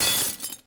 terumet_break.3.ogg